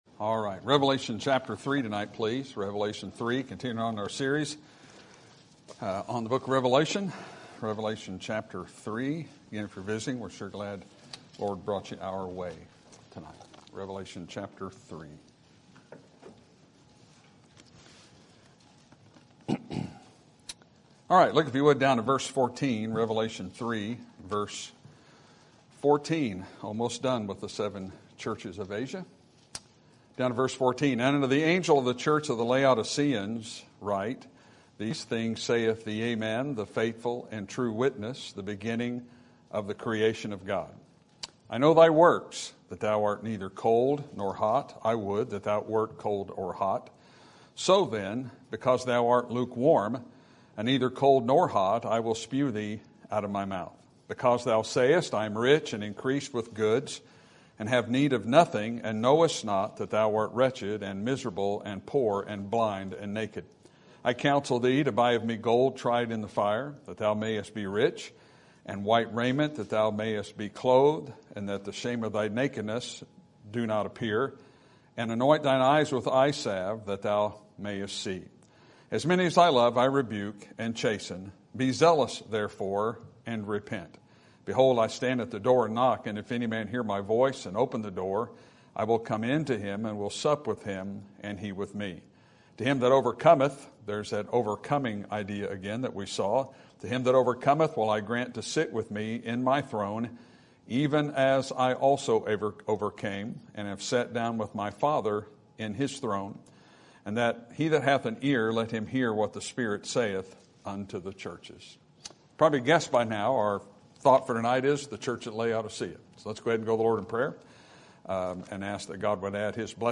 Sermon Topic: Book of Revelation Sermon Type: Series Sermon Audio: Sermon download: Download (24.83 MB) Sermon Tags: Revelation John Saviour Churches